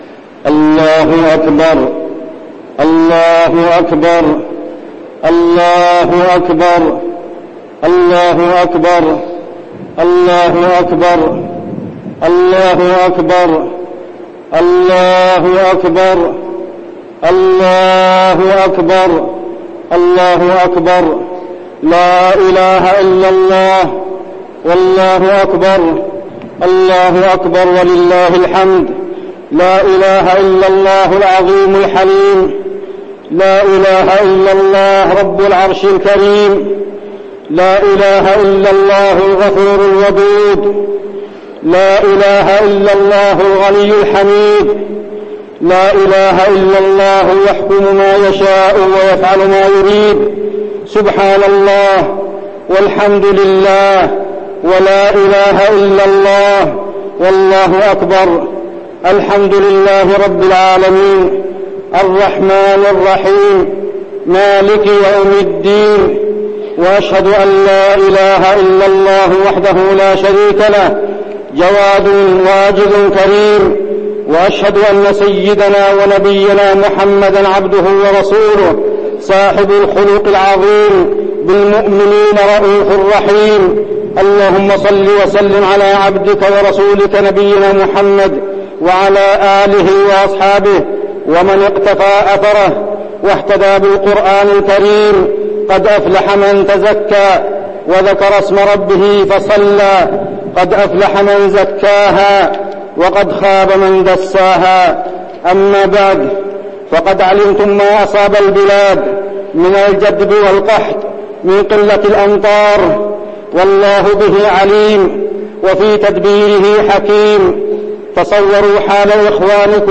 خطبة الاستسقاء
المكان: المسجد النبوي